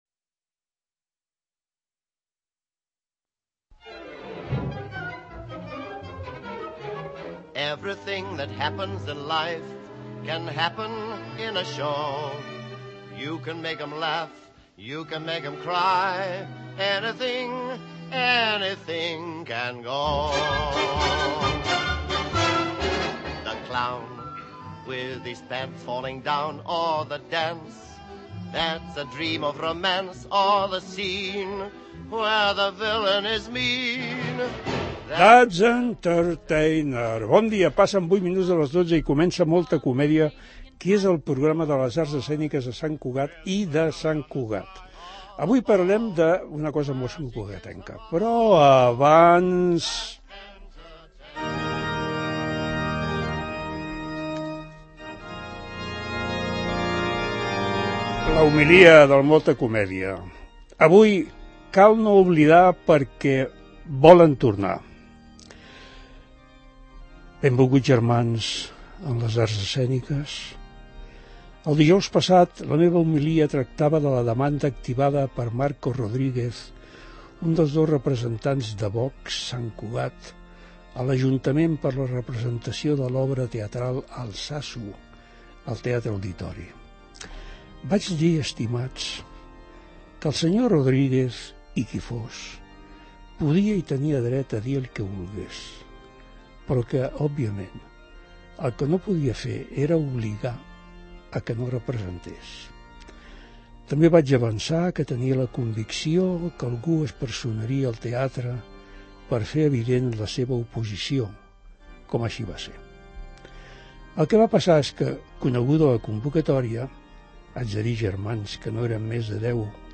visiten el programa per parlar de l�espectacle 'Rizomes, un vespre d�arrels' que es presentar� a la Uni� Santcugatenca el proper diumenge 8 a les 19 hores a la Sala Clav�.